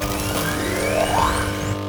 charge1.wav